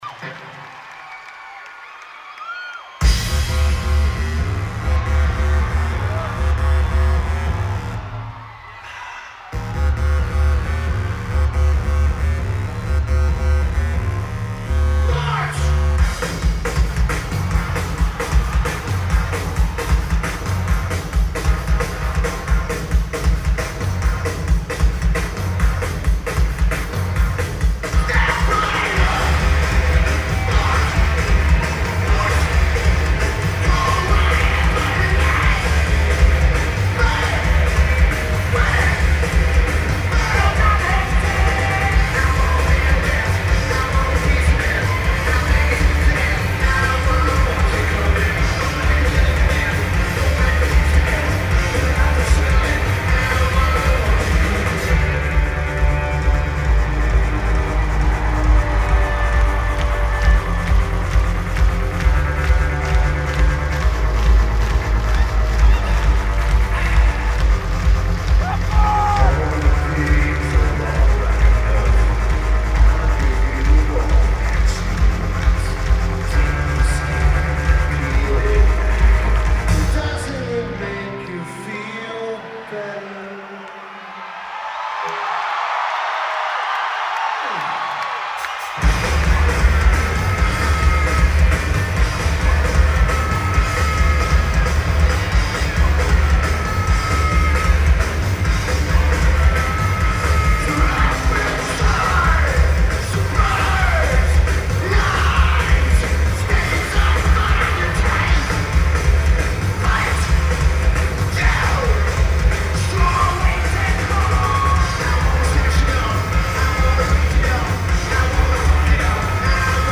Lineage: Audio - AUD (DPA 4061s + Sony PCM-M1)
Great recording and the best of the night's sources.